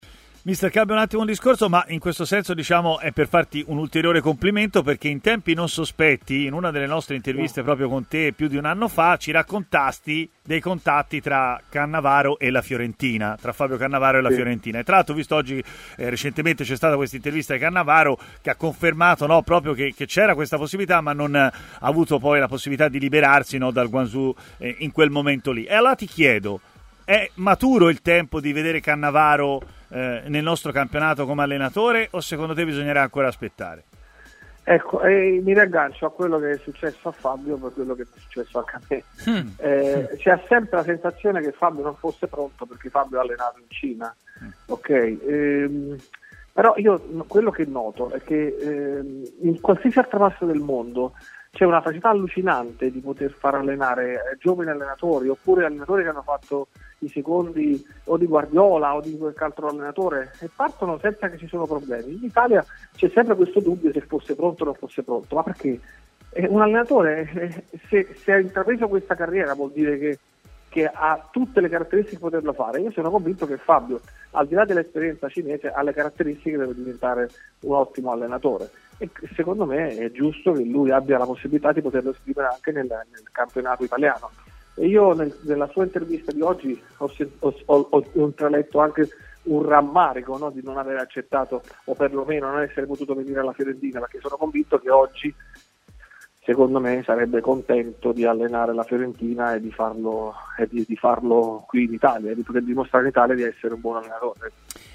è intervenuto in diretta a Stadio Aperto, trasmissione di TMW Radio.